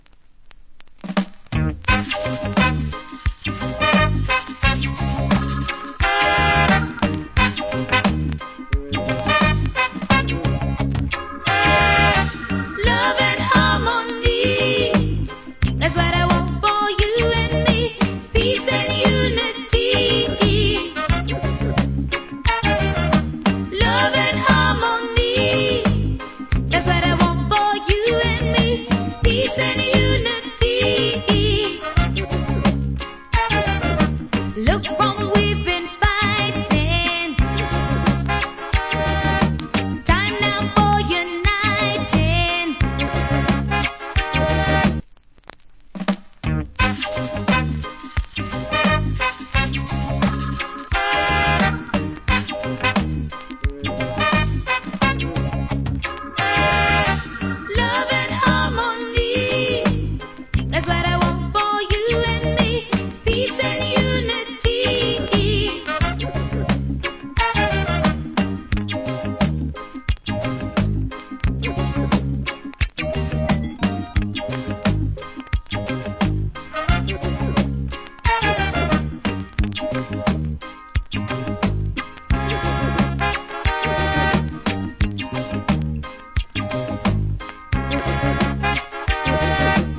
Nice Female Reggae Vocal.